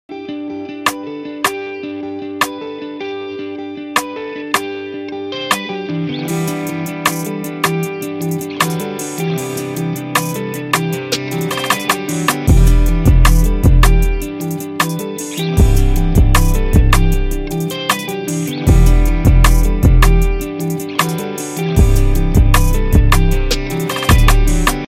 guitar beat